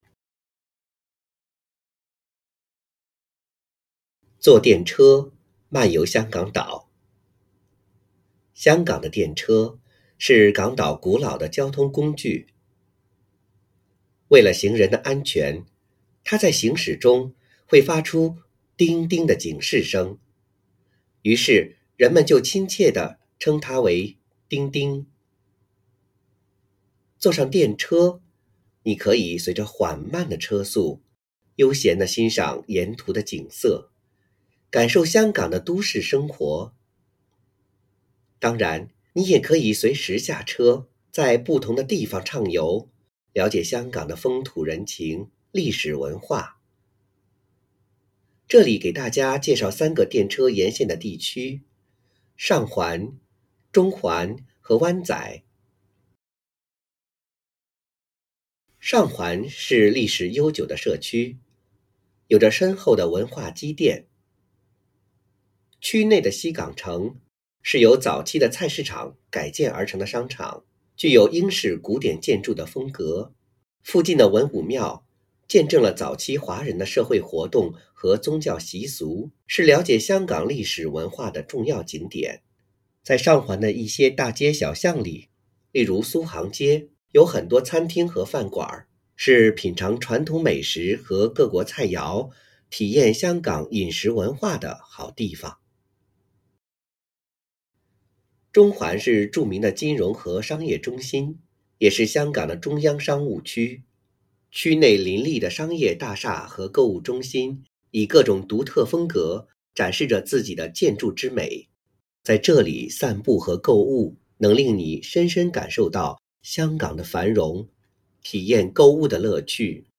編號篇名篇章及學與教建議朗讀示範篇章附拼音